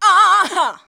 AHA 3.wav